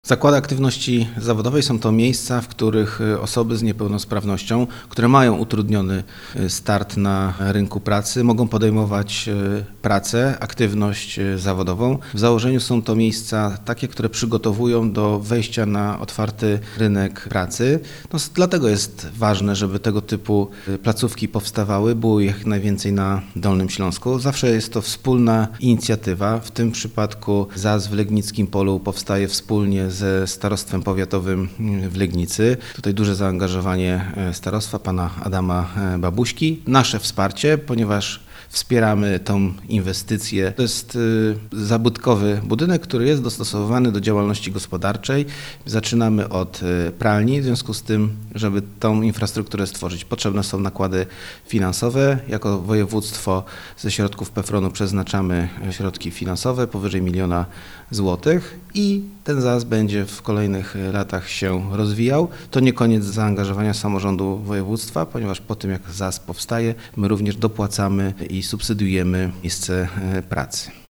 Czym są Zakłady Aktywności Zawodowej – tłumaczy Marcin Krzyżanowski – Wicemarszałek Województwa Dolnośląskiego.